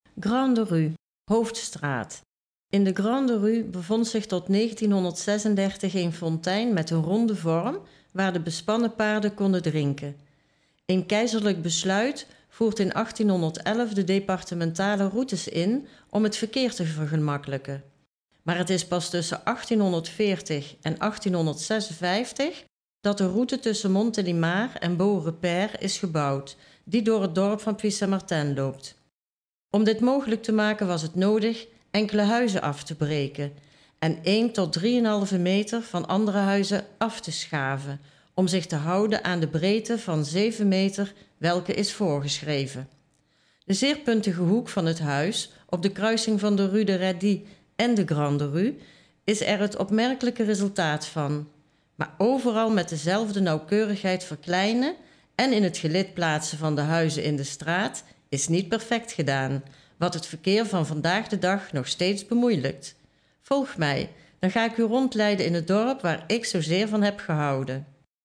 Als de QR-code, zoals hieronder en op elk ander paneel is weergegeven, gescand wordt met een smartphone, zal de bezoeker toegang krijgen tot een audio-gids in het Nederlands, wat zijn bezoek zal verrijken